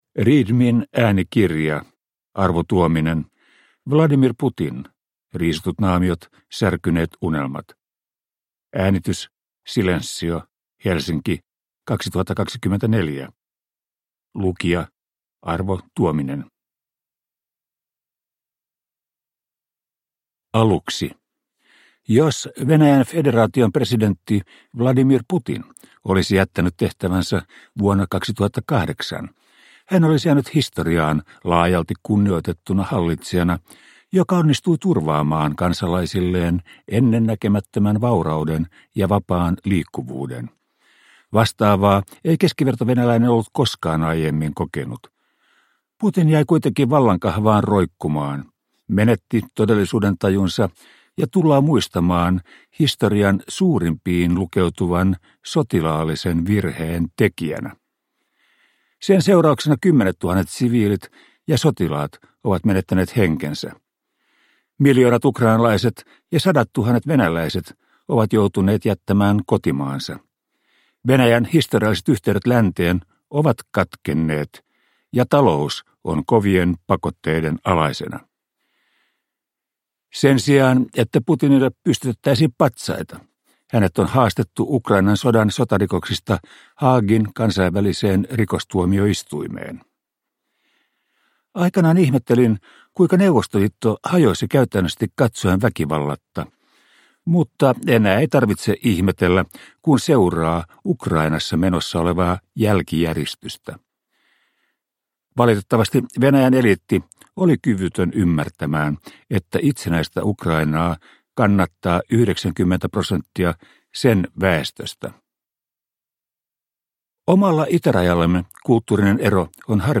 Vladimir Putin - Riisutut naamiot, särkyneet unelmat (ljudbok) av Arvo Tuominen